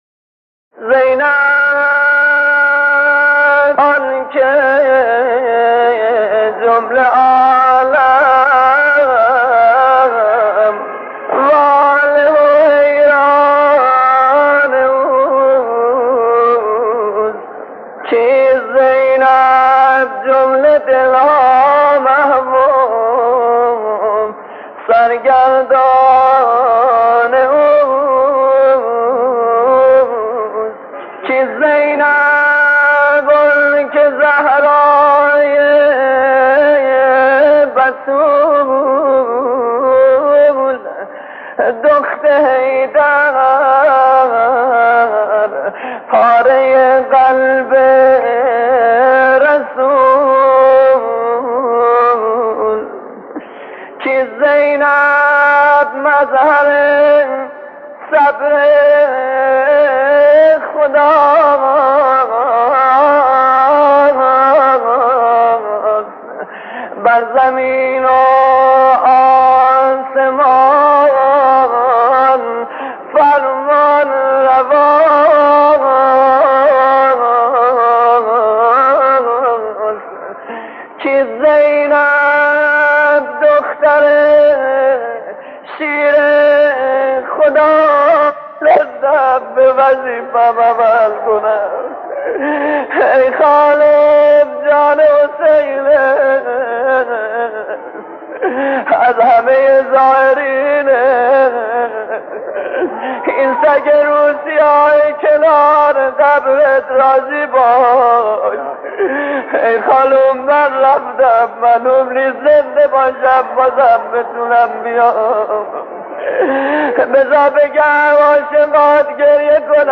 در پرده عشاق، صدای مداحان و مرثیه‌خوانان گذشته تهران قدیم را خواهید شنید که صدا و نفس‌شان شایسته ارتباط دادن مُحب و مَحبوب بوده است.
منقبت‌خوانی و مصیبت‌خوانی درباره علاقه حضرت زینب (س) به سیدالشهدا (ع) و گریز و اشاره به عصر عاشورا